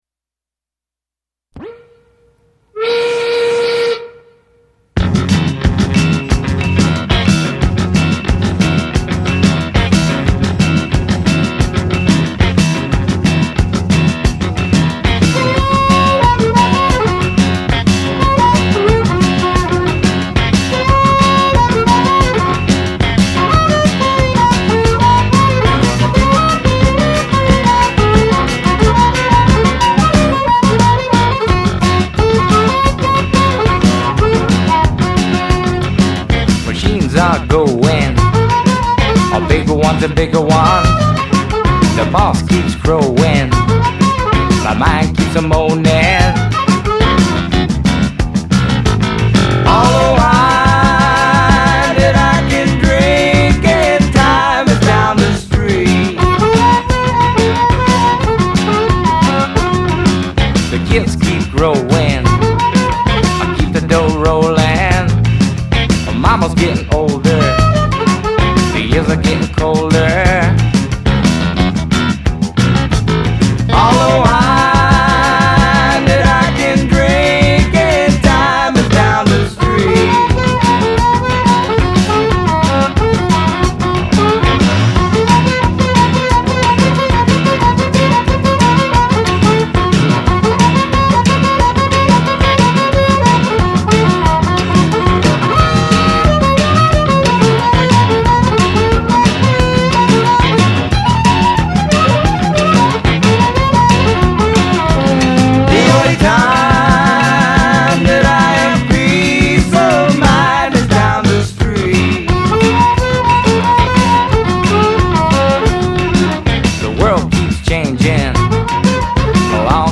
Harmonica, background vocals
Guitar
Lead vocal, drums, baseball bat
Gibson EB3 electric bass, clavinet